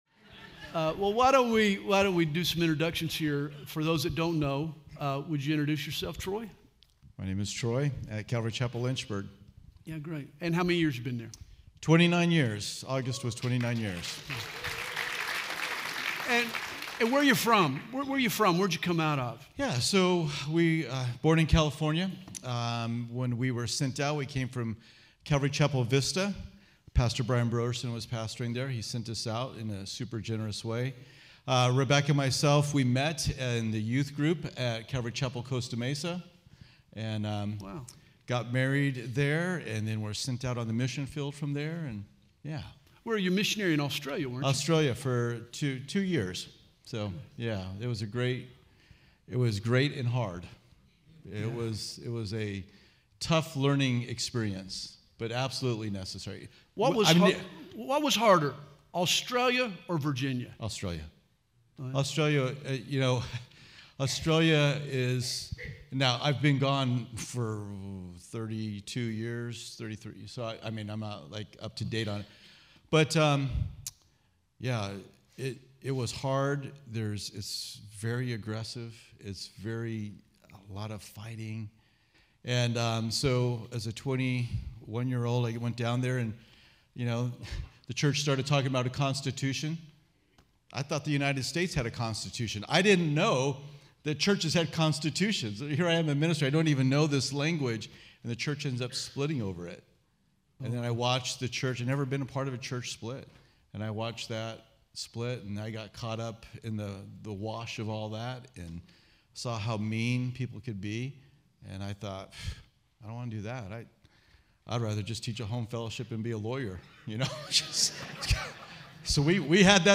2023 DSPC Conference: Pastors & Leaders